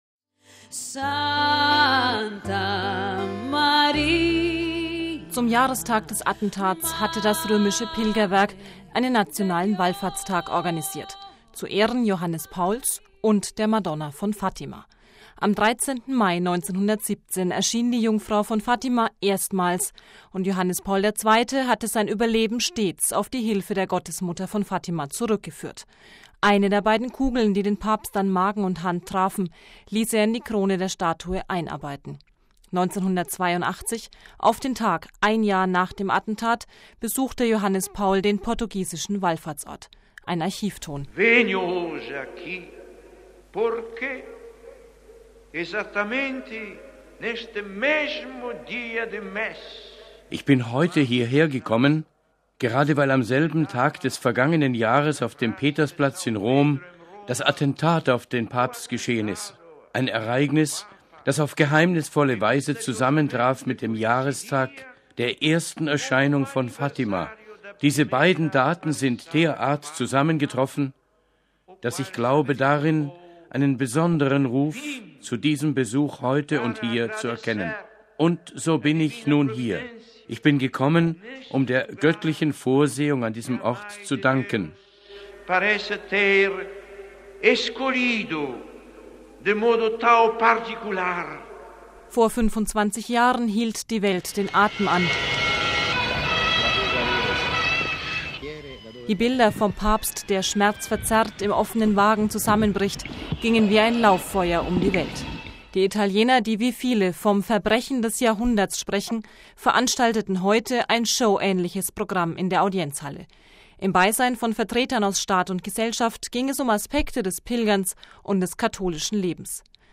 Seine Stimme schwach, seine Botschaft keine zehn Zeilen lang: